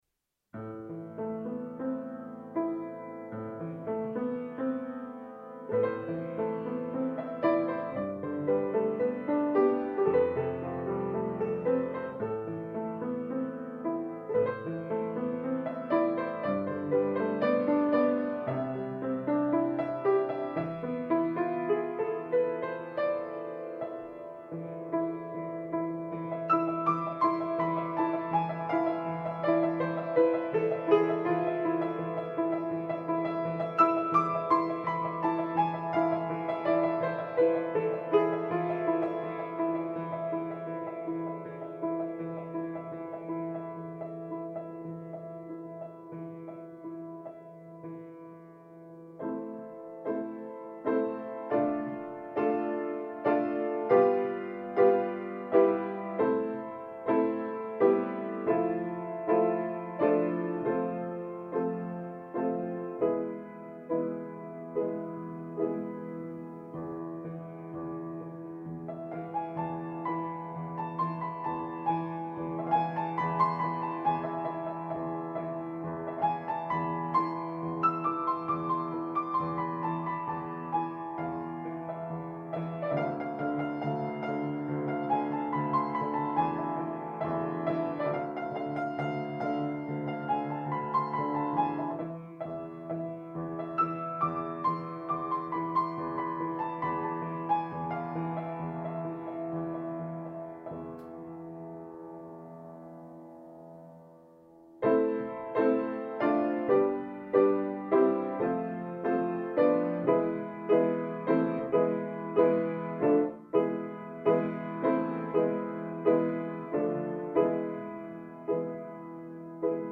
خواننده و آهنساز